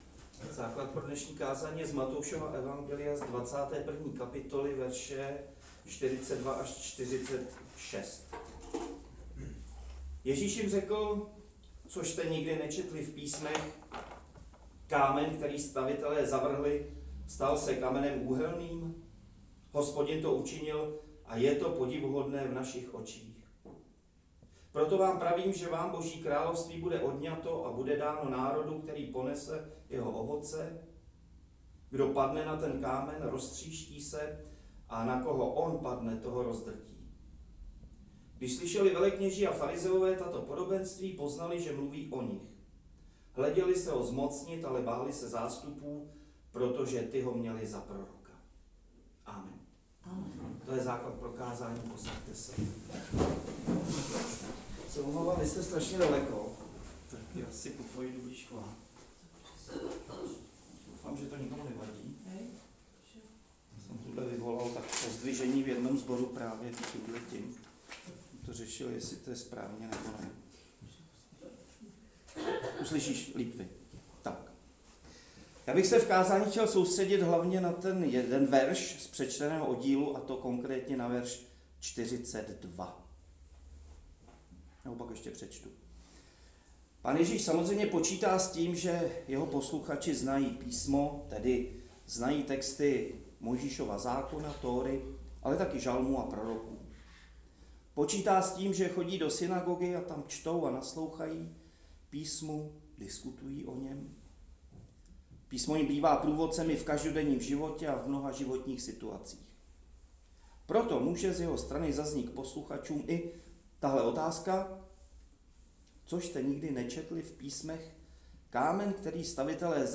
Neděle Sexagesimae (60 dní do Velikonoc) 23. února 2025
Kázání